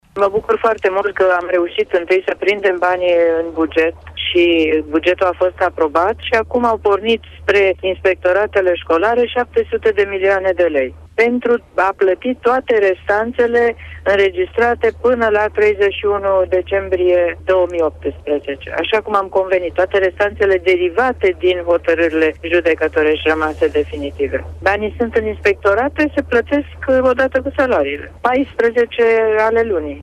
Ea spus la Radio România că banii vor fi plătiţi odată cu salariul de luna aceasta: